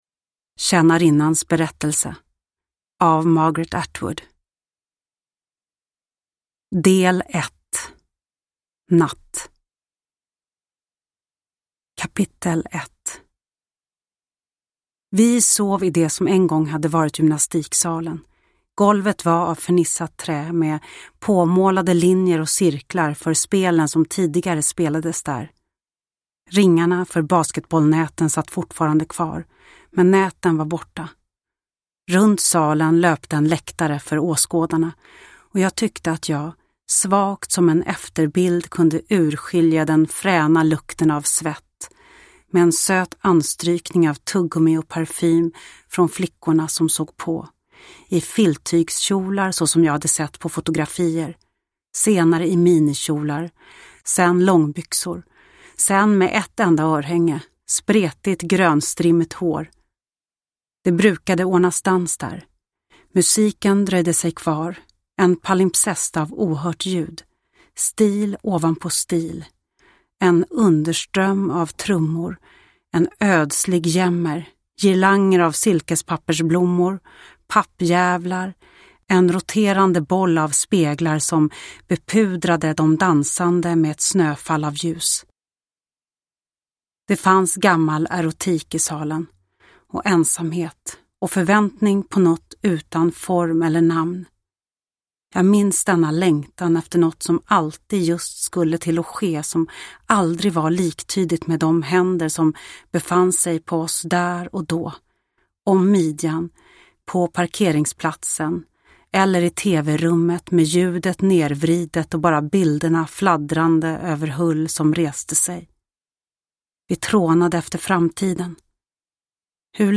Tjänarinnans berättelse – Ljudbok – Laddas ner
Uppläsare: Lo Kauppi